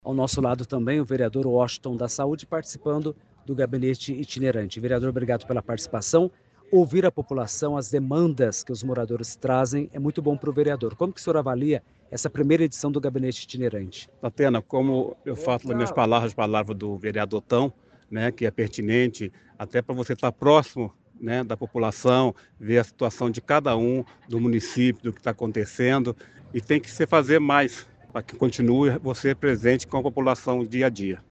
Áudio do vereador Washington Batista Cavalcante (Washington da Saúde – Republicanos);